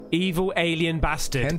evil-alien.mp3